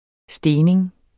Udtale [ ˈsdeːneŋ ]